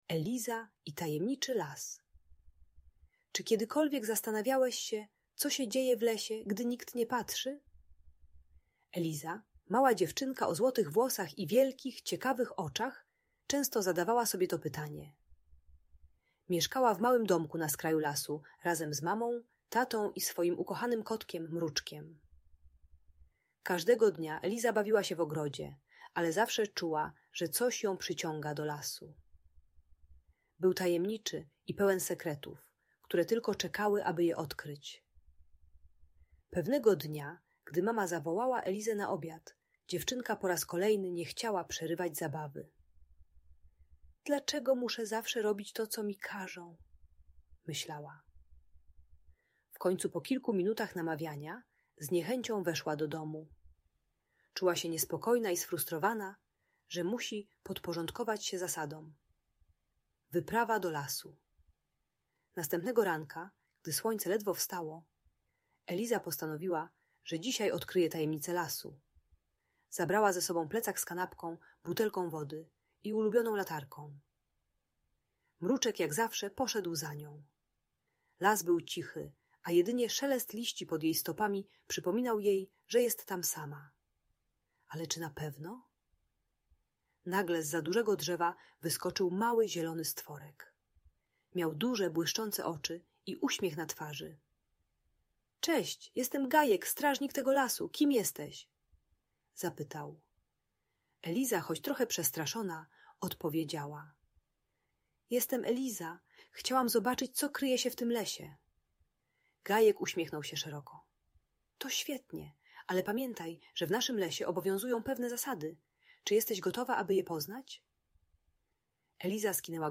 Eliza i Tajemniczy Las - historia o odkrywaniu zasad - Audiobajka